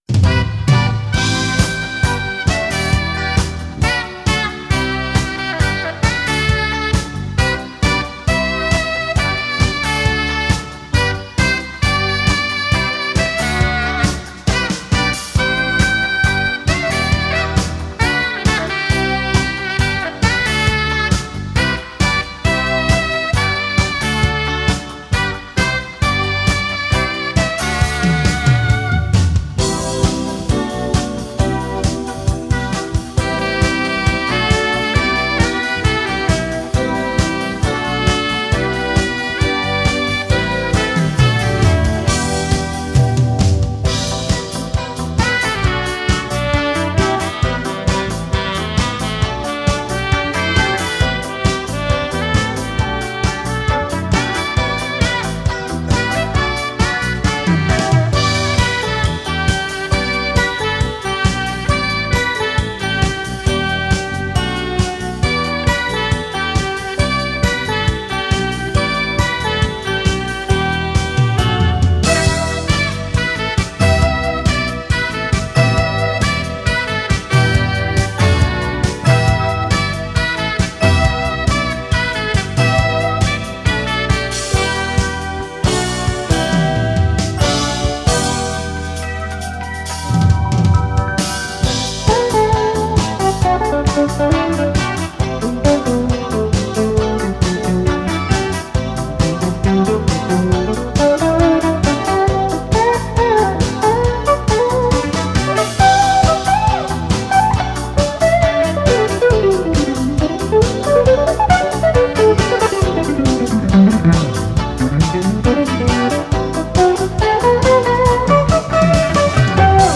saxophone.
electric guitar solo.